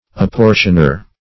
Apportioner \Ap*por"tion*er\, n.